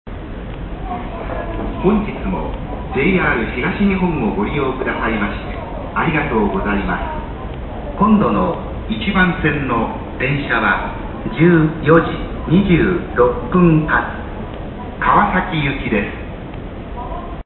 次発放送川崎行き 川崎行きの次発放送です。
行き先を言う際、「川崎行きです」と一続きにいうようになった。